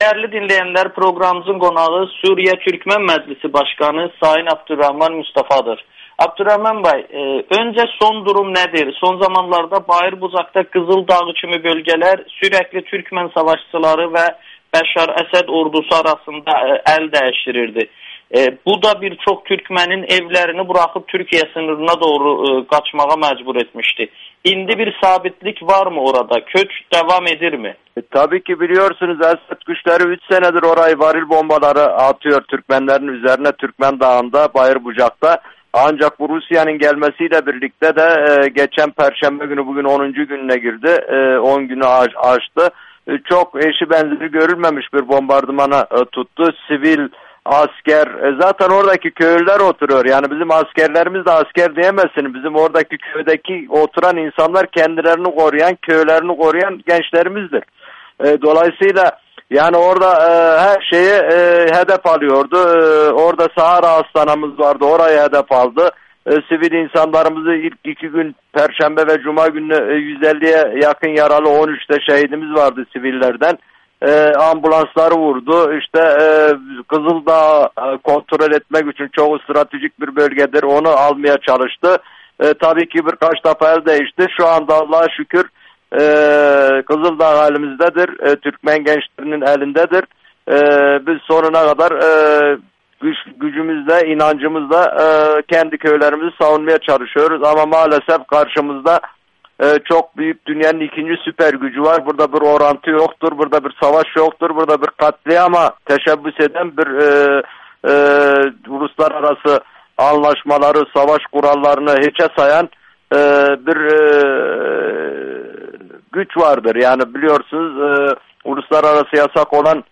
Suriya türkmənlərinin lideri Amerikanın Səsinə danışır